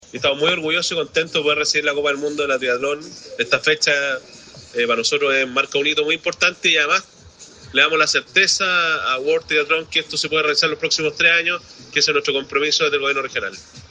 En tanto, el gobernador Sergio Giacaman reiteró el compromiso de respaldar esta iniciativa por los próximos tres años.